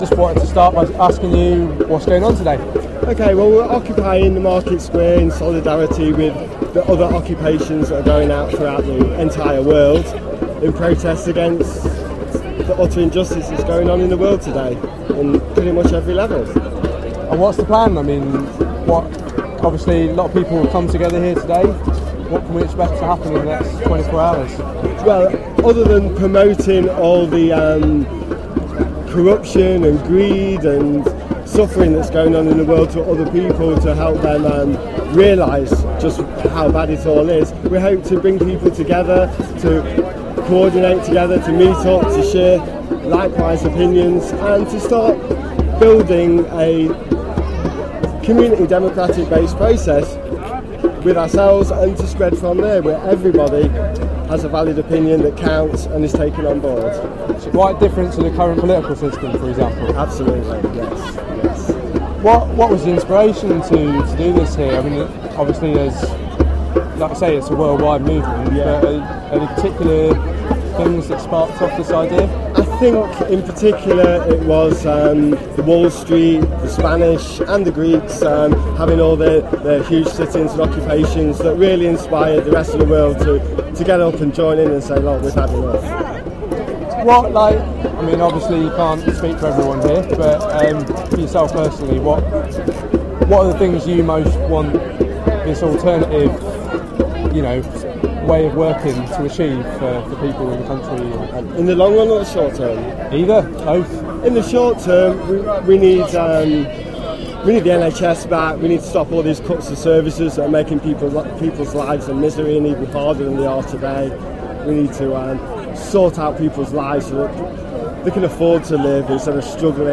Starting from around 3pm today, Nottingham's Market Square has been occupied by a gathering of people aiming " to show that we will no longer tolerate the corporate greed and Government corruption that threatens our way of life and everything we work for" .
The occupiers have been enjoying coming together in the sunshine, tents have been set up and a megaphone is being passed around for people to communicate their frustrations with the system.
At one point a quite drunk man heckled speakers shouting "Get a job!" much to the embarrassment of his friend.